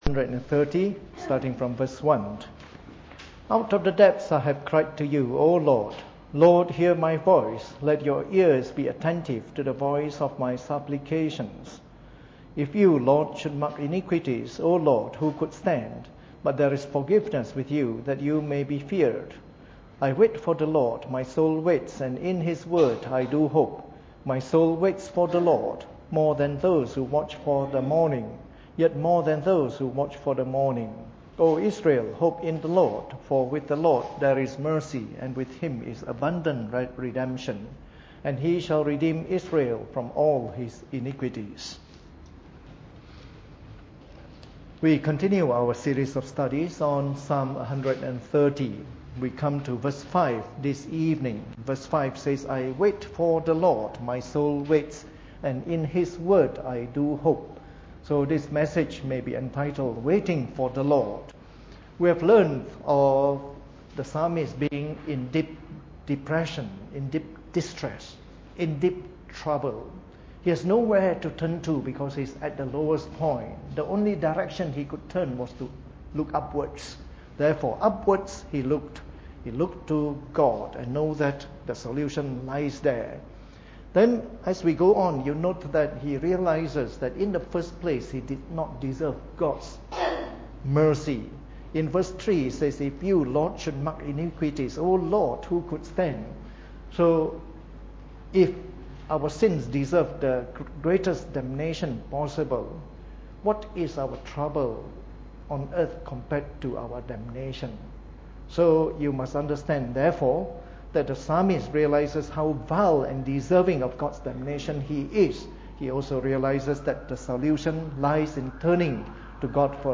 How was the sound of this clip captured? Preached on the 21st of August 2013 during the Bible Study, from our series of talks on Psalm 130.